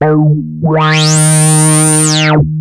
OSCAR  9 D#3.wav